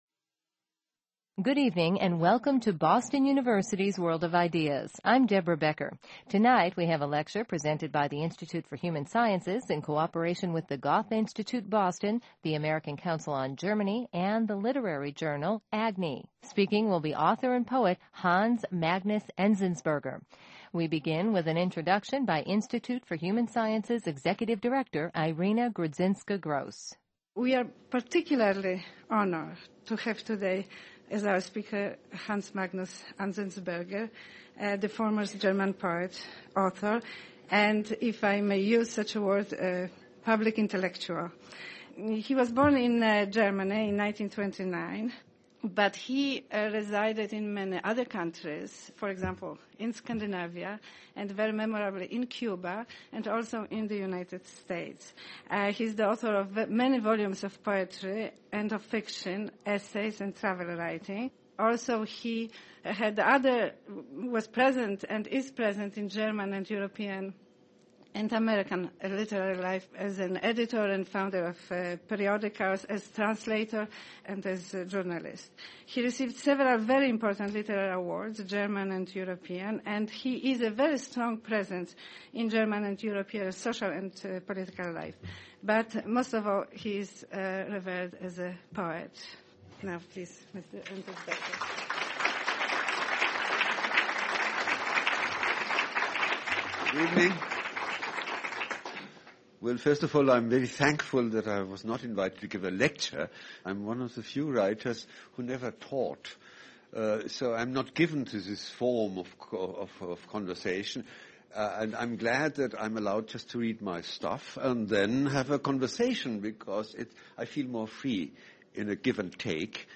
Discussions and lectures by the best and brightest thinkers from Boston University
This show presented excerpts from a poetry reading and conversation with German author and poet, Hans Magnus Enzensberger.